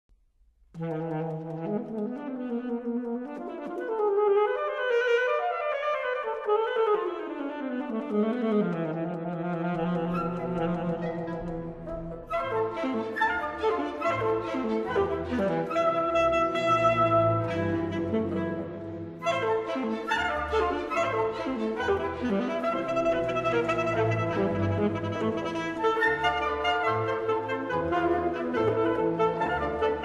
Allegro 2:56